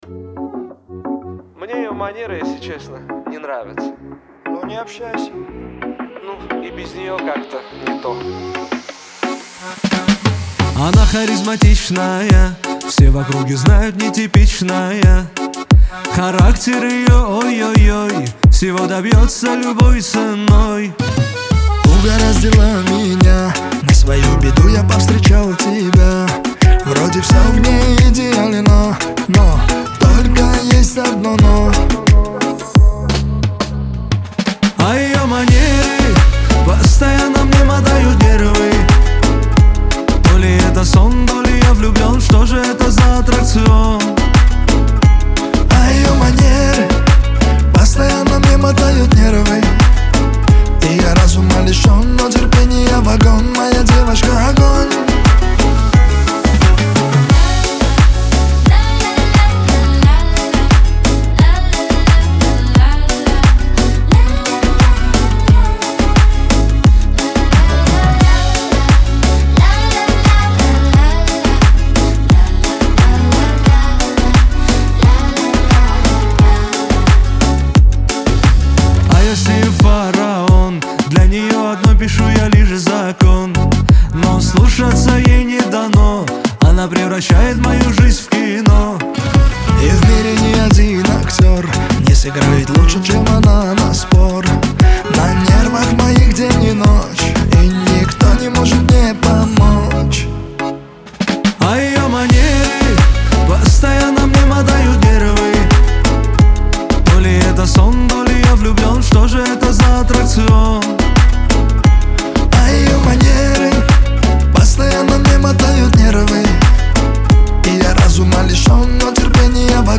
Кавказ – поп
диско
дуэт